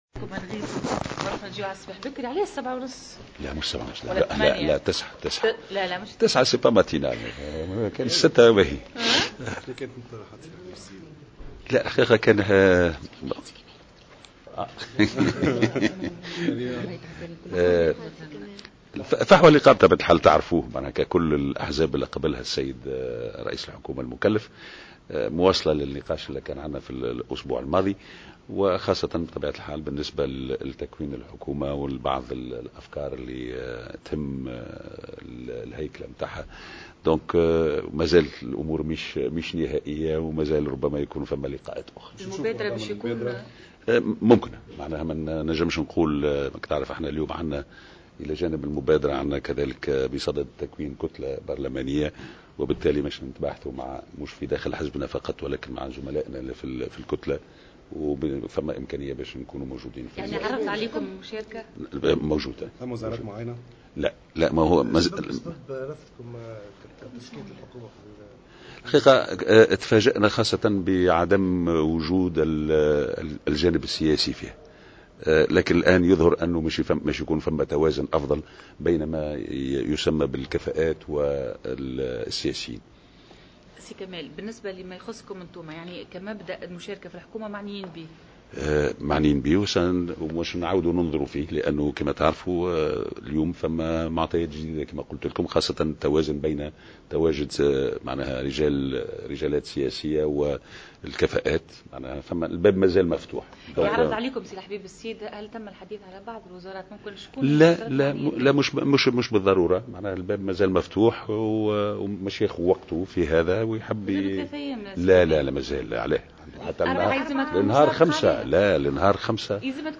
Kamel Morjane a affirmé aujourd’hui dans une déclaration accordée à Jawhara FM, que parti Al Moubadra pourrait participer au gouvernement Essid, et ce suite à sa réunion avec le chef de gouvernement désigné Habib Essid.